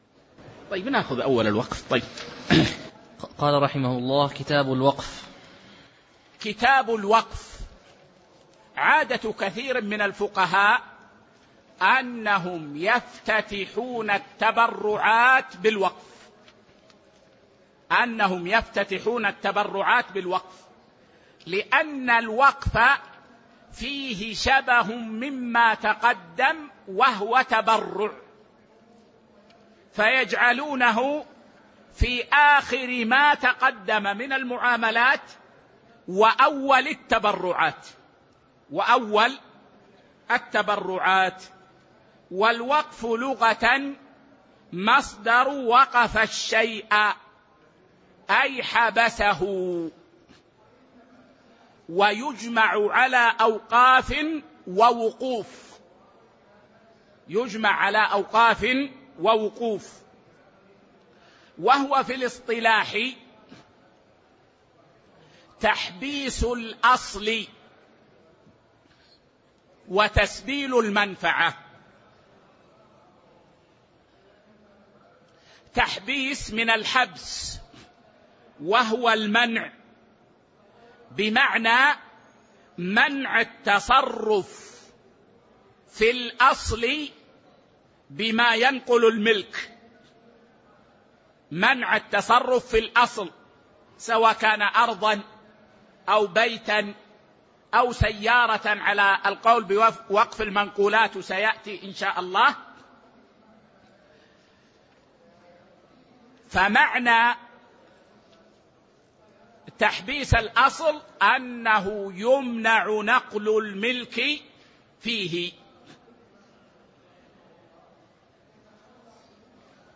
تاريخ النشر ١١ ربيع الثاني ١٤٤٤ هـ المكان: المسجد النبوي الشيخ